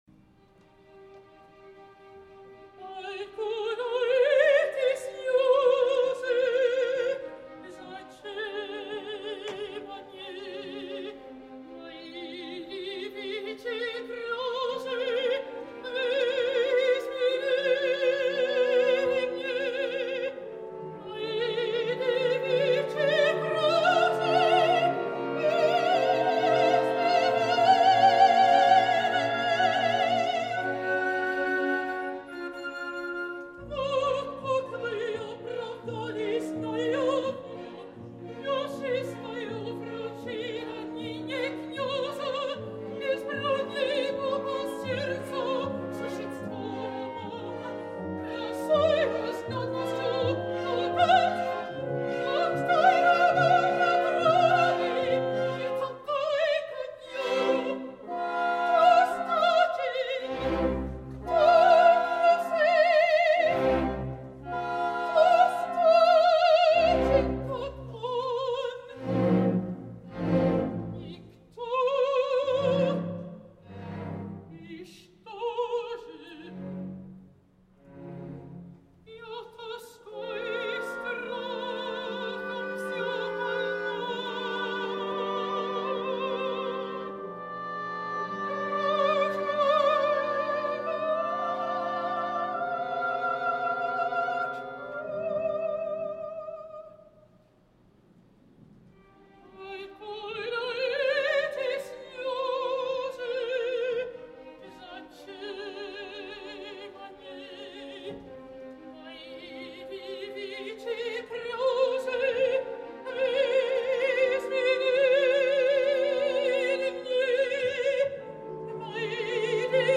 A finals de mes arriba al Gran Teatre del Liceu la Pikovaia Dama de Txaikovski, i amb aquest títol cabdal no només de l’opus del compositor, si no de l’òpera en general, torna la soprano americana/canadenca Sondra Radvanovsky, en un rol magnífic per a les possibilitats vocals i dramàtiques, diria que fins i tot d’afinitat eslava, no debades el pare de Radvanovsky va néixer a Txèquia, tot i que el repertori eslau no ha estat fins ara gaire present en la seva carrera.
Per fer-nos una idea del que podem arribar a gaudir de la seva Lisa us deixo uns quants fragments de la representació que va tenir lloc en el mes de febrer de l’any 2020 a l’òpera de Chicago, sota la magnífica direcció de Sir Andrew Davis.
El rol de Lisa és intens i necessita d’una soprano  spinto, molt expressiva i que pugui anar amb facilitat del registre greu, la part més feble de la veu de Radvanovsky, a la part més aguda, on no hi ha de moment cap temor.
Tenint en compte que la veu de Radvanovsky es gaudeix molt més al teatre que en els enregistraments, ja que difícilment la seva gran veu s’esplaia com a la sala on corre i omple tots els racons, de ben segur que aquesta intensitat que s’aprecia en els àudios que us deixo a l’apunt, fan albirar per la seva part un triomf com els que ens té tan ben acostumats.